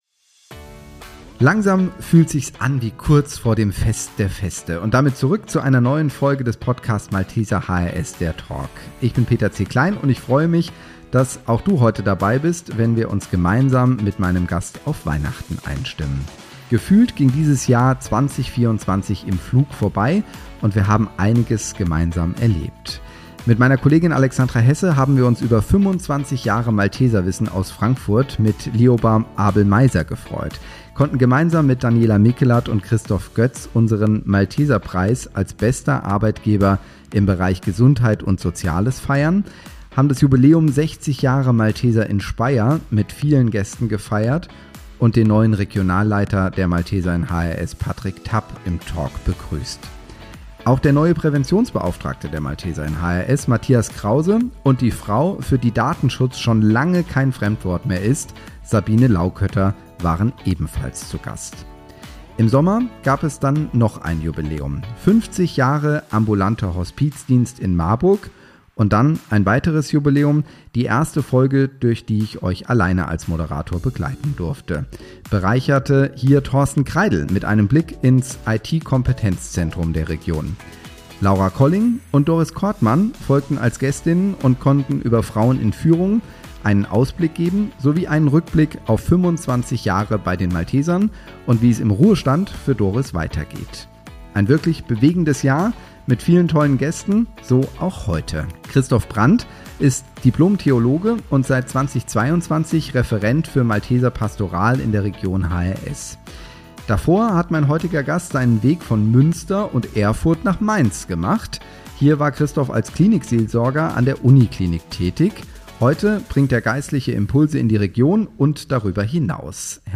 Beschreibung vor 1 Jahr Was passt besser in eine Weihnachtsfolge als ein Kollege aus dem Bereich Malteser Pastoral.